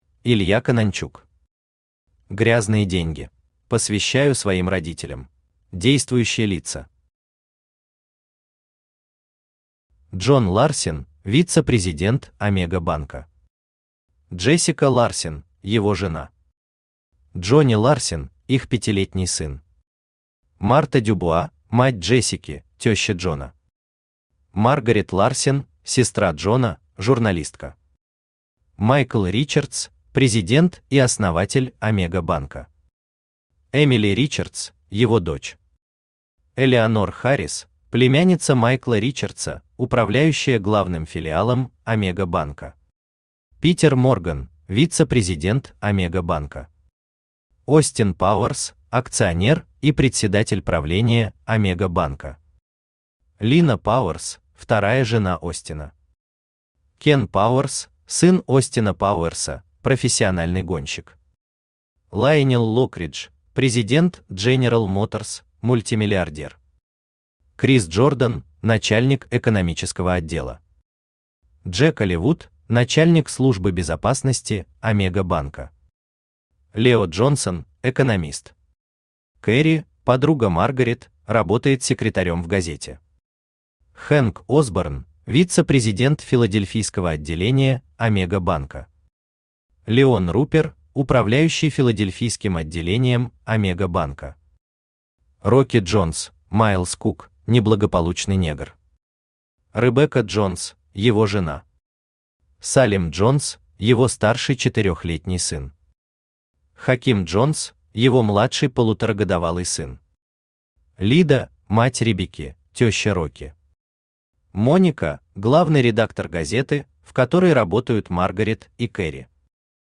Aудиокнига Грязные деньги Автор Илья Конончук Читает аудиокнигу Авточтец ЛитРес.